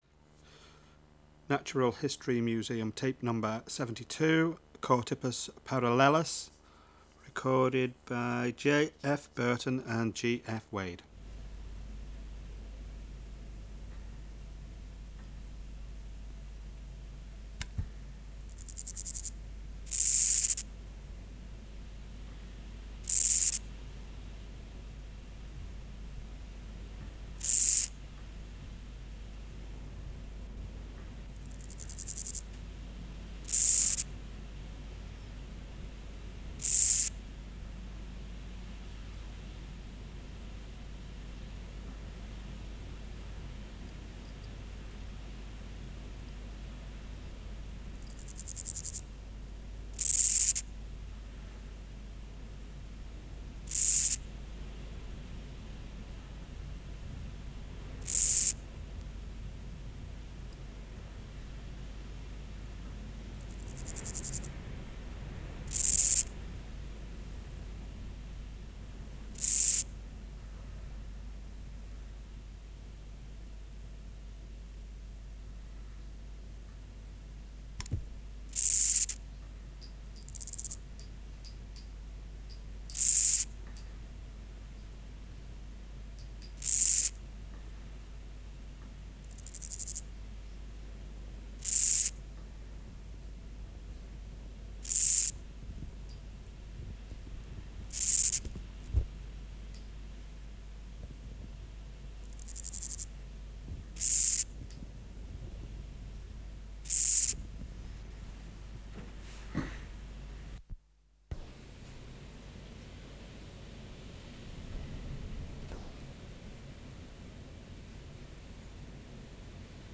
Species: Chorthippus parallelus